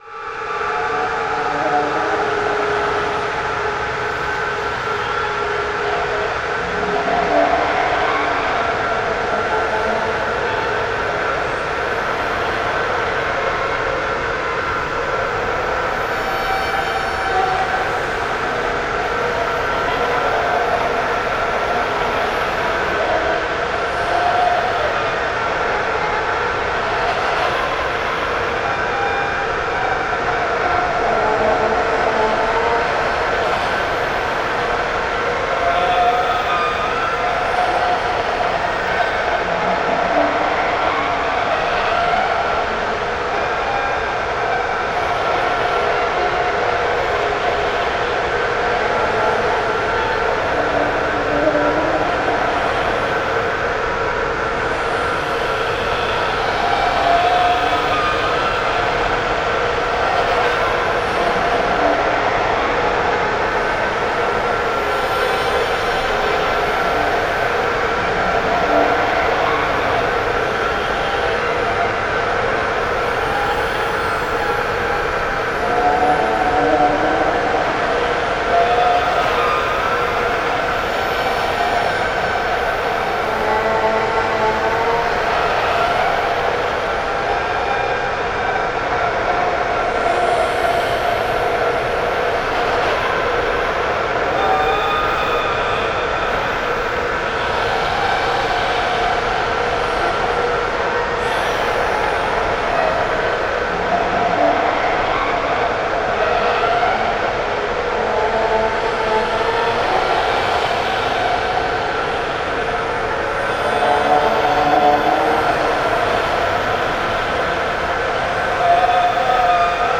horror soundscapes